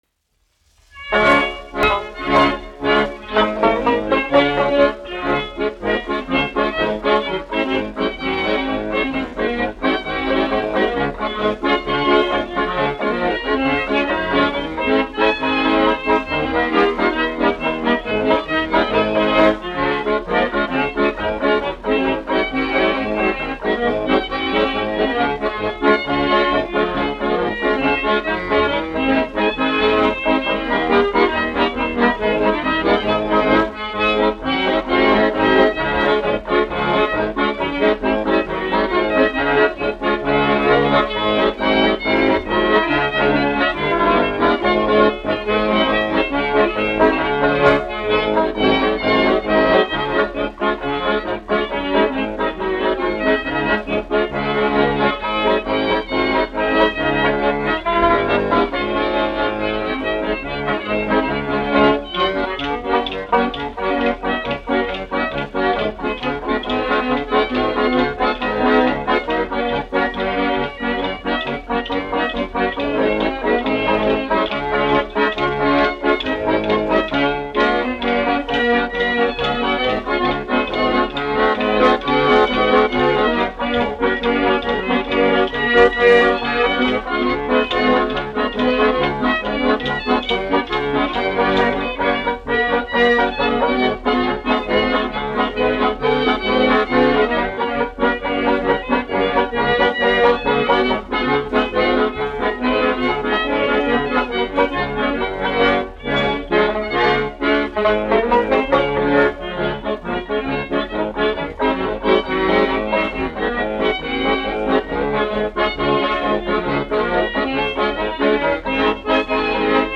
1 skpl. : analogs, 78 apgr/min, mono ; 25 cm
Marši
Populārā mūzika
Skaņuplate